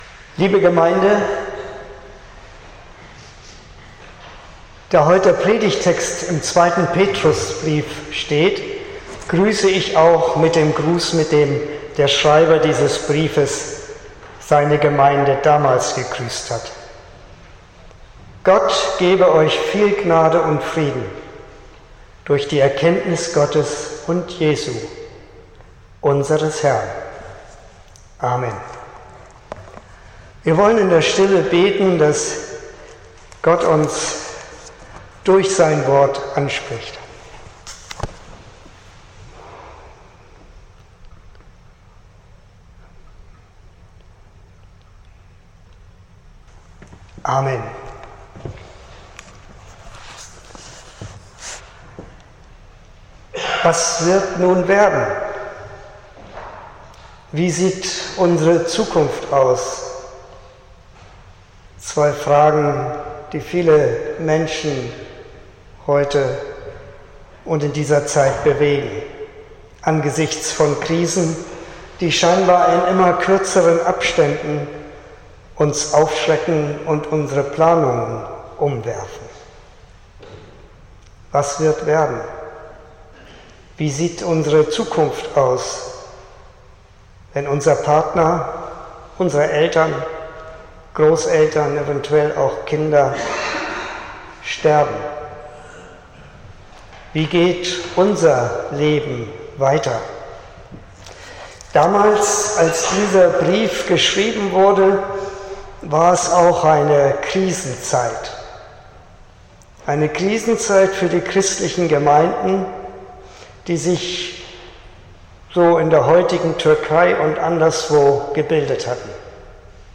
26.11.2023 – Gottesdienst
Predigt (Audio): 2023-11-26_Warten_lohnt_sich.mp3 (20,1 MB)